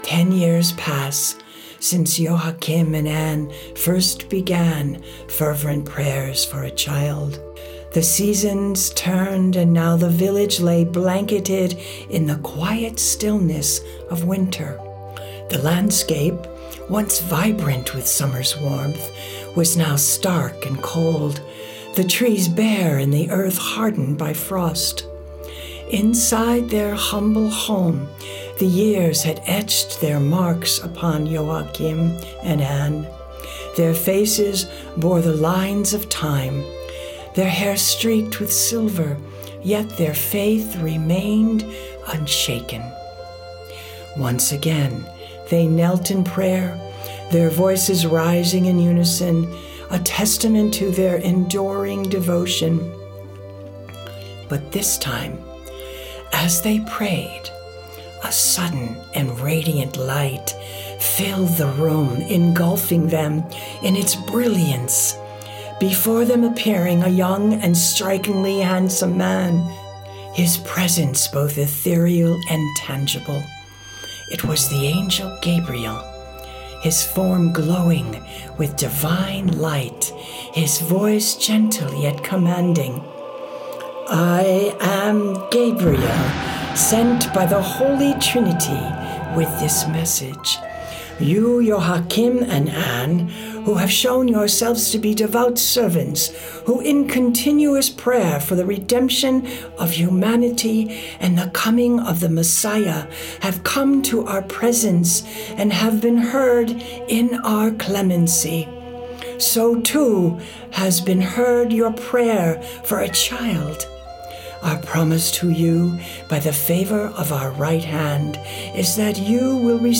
From Blessed is Her Name - Audio book